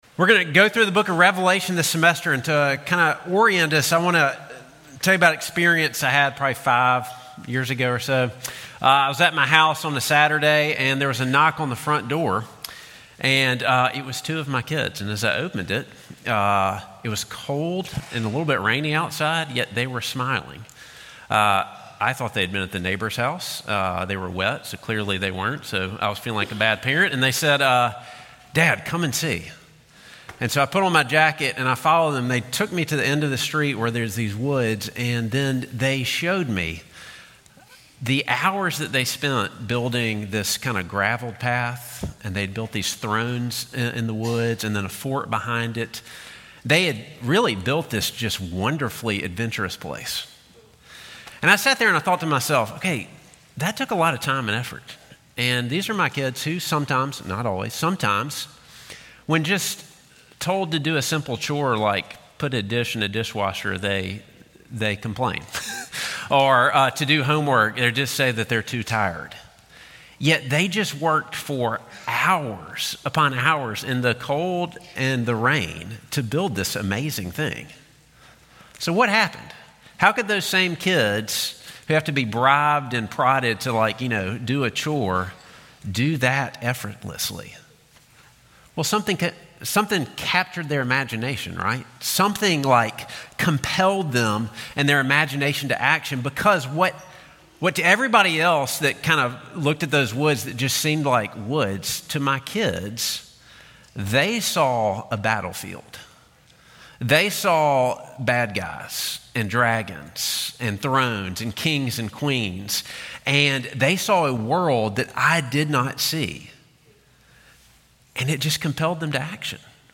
Sermon Points: